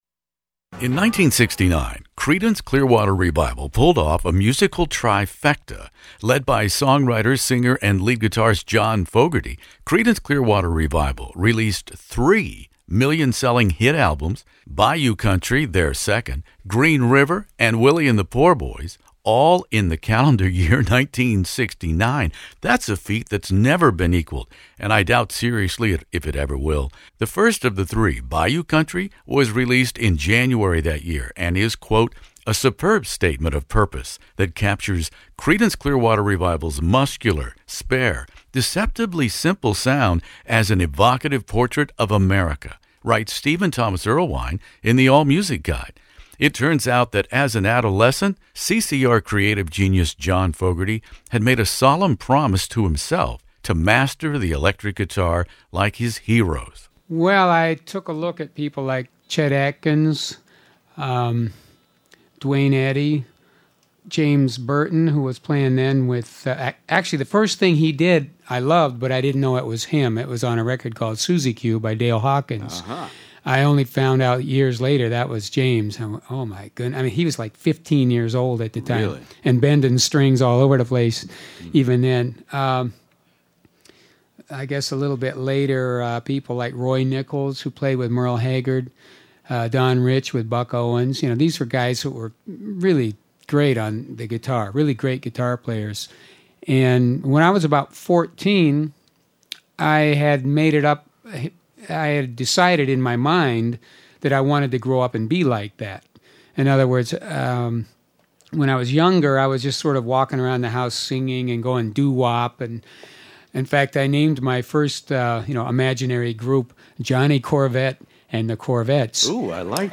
This is my very rare interview with John Fogerty, who wrote and sang every Creedence Clearwater Revival song, played lead guitar & great chunky rhythm guitar, and produced every CCR album.